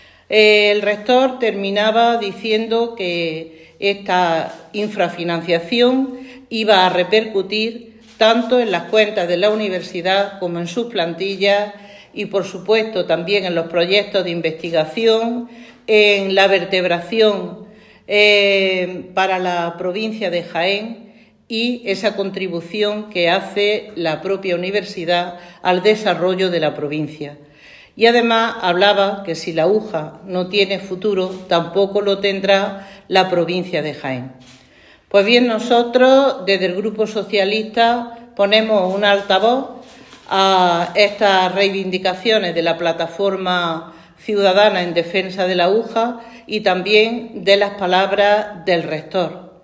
En rueda de prensa
Cortes de sonido